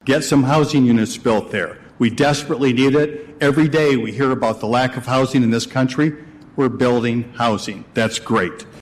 Mayor Dave Anderson says it’s not the low income affordable housing they need the most, but they also need homes for this income group.